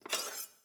SFX_Cooking_Knife_PickUp_01_Reverb.wav